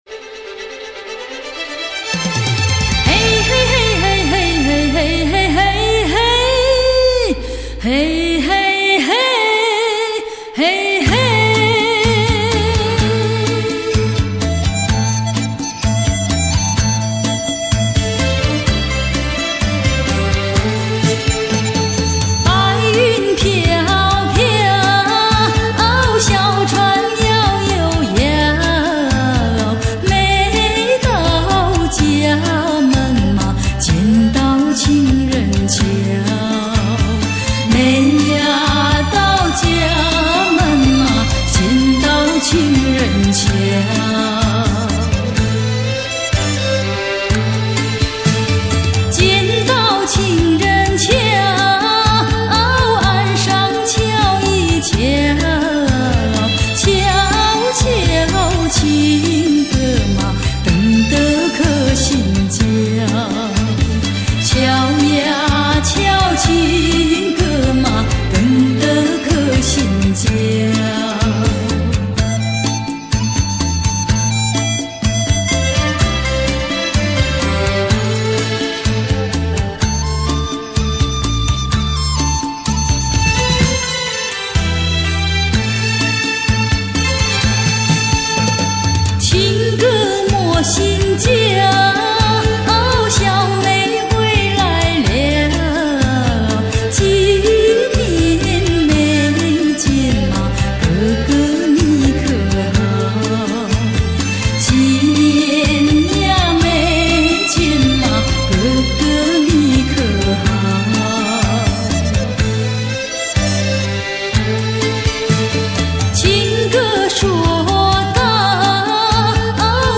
震天动地的强劲动感 举座皆惊的靓绝旋律